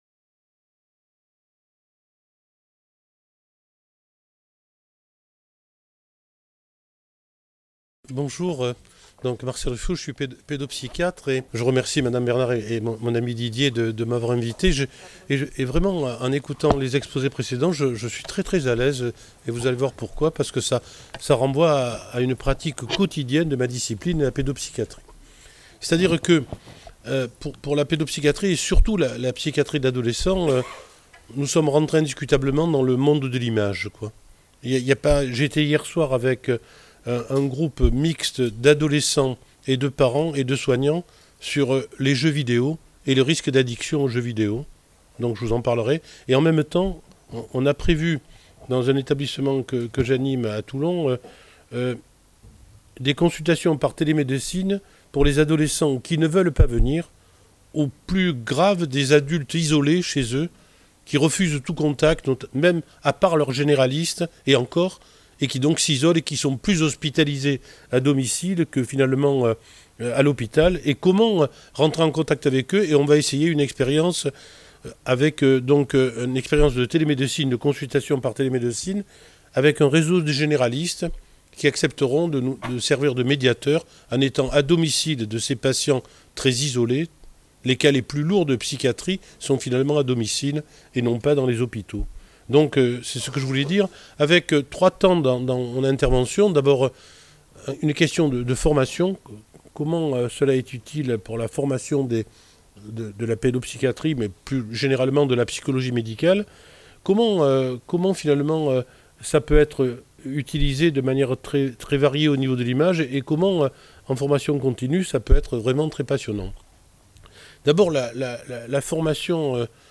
Rencontre de Printemps 2019 : Conférence du Professeur Marcel RUFO | Canal U
le 22 mars à l'Université de Paris, Faculté de médecine Paris Diderot, site Villemin - Paris